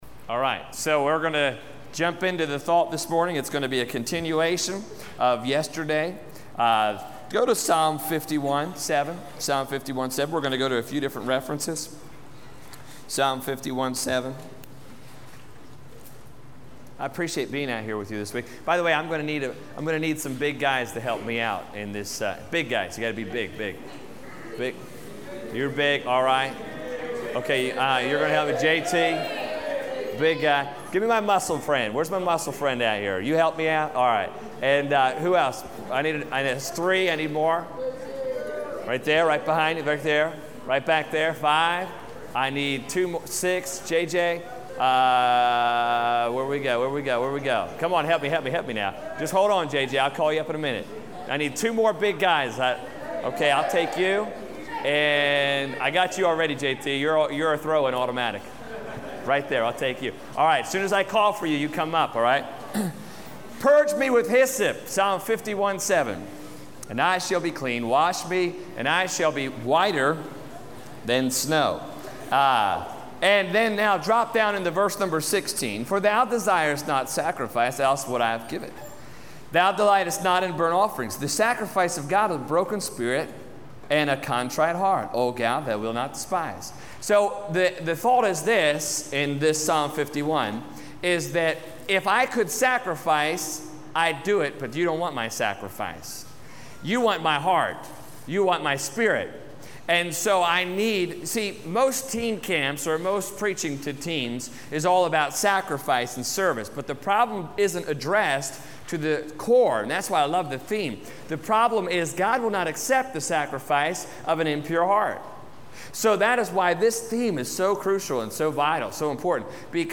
Series: Teen Camp 2009 Service Type: Teen Camp